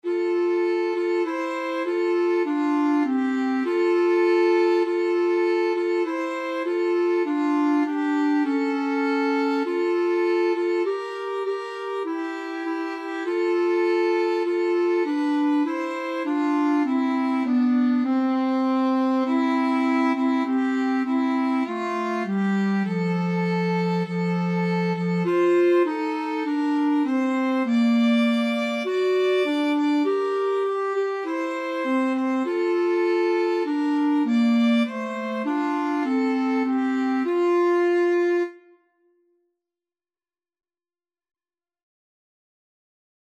Free Sheet music for Clarinet-Violin Duet
F major (Sounding Pitch) G major (Clarinet in Bb) (View more F major Music for Clarinet-Violin Duet )
4/4 (View more 4/4 Music)
Classical (View more Classical Clarinet-Violin Duet Music)